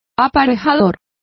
Complete with pronunciation of the translation of riggers.